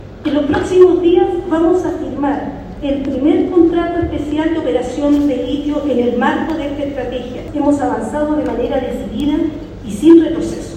Cena anual de Sonami
En su intervención, Williams adelantó que en los próximos días se firmará el primer contrato especial de operación dentro de la estrategia nacional impulsada por el Gobierno, destacando que estos avances reflejan el rol estratégico de Chile en la transición energética global.